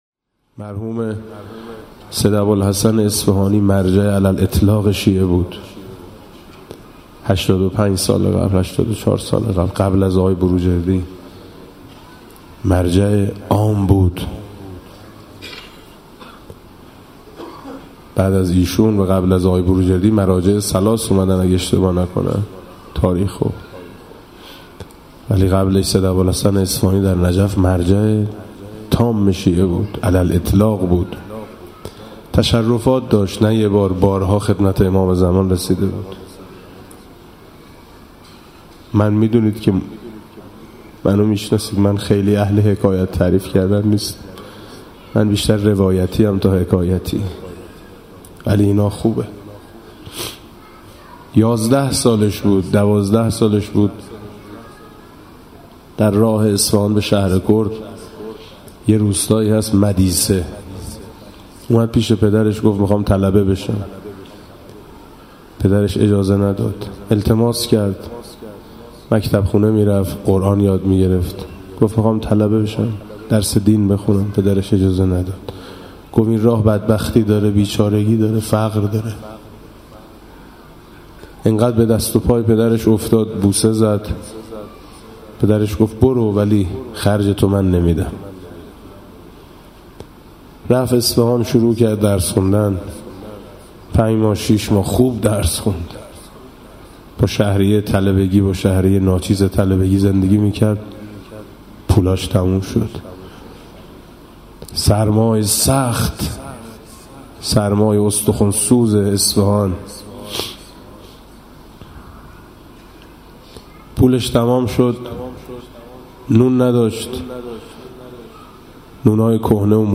روایت